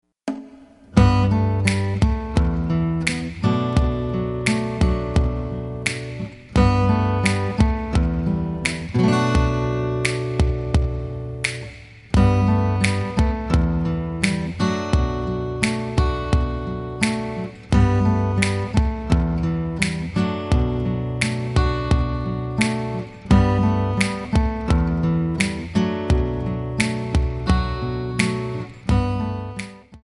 MPEG 1 Layer 3 (Stereo)
Backing track Karaoke
Pop, 1990s